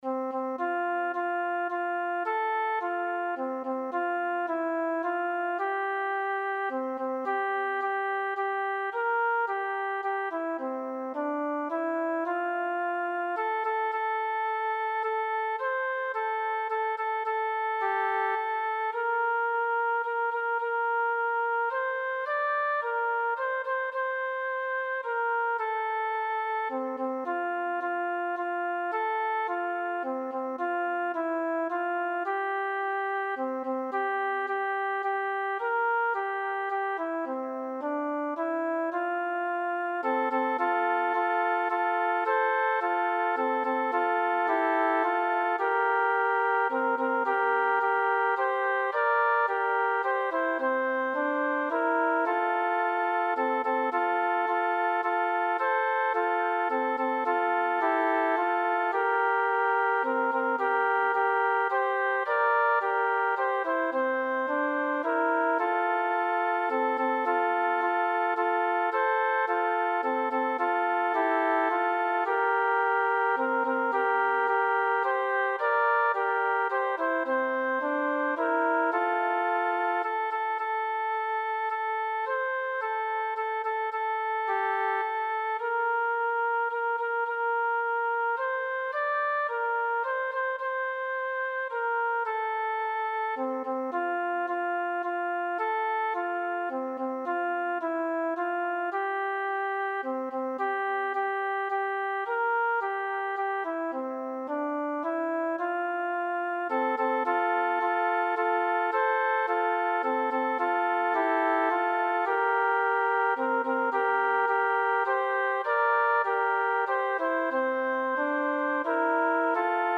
3º Música Primavera, 4º Música Flauta, 4º Música Primavera | 0 Comentarios
“Canon sencillo para empezar al final de 2º- principios de 3º„